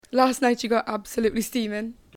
Examples of Welsh English
//lɑst nɑɪt ʃi gɒt ˈɑbsəluːtli ˈstiːmɪn//
The pronunciation of /ŋ/ as /n/ in the word steaming is typical of many non-standard varieties of English.